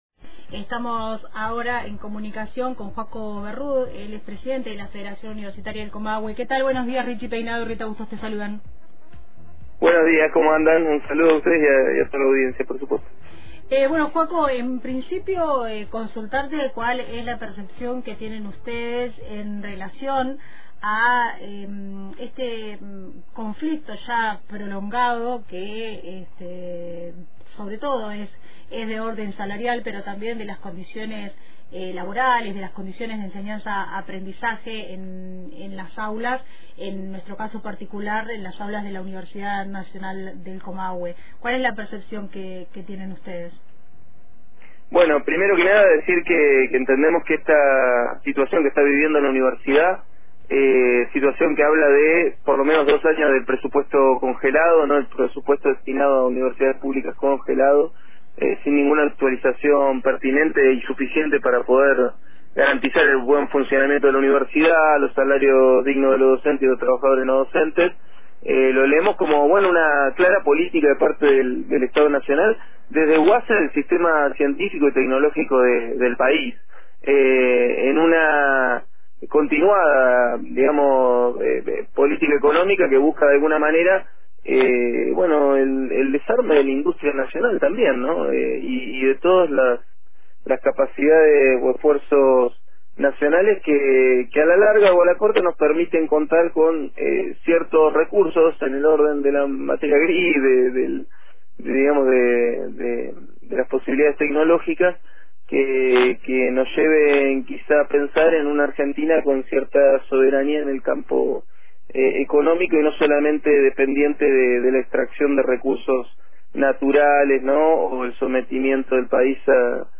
En diálogo con Antena Libre, señaló que la falta de actualización de fondos afecta tanto el funcionamiento de las universidades como los salarios docentes, las becas y las condiciones de cursada.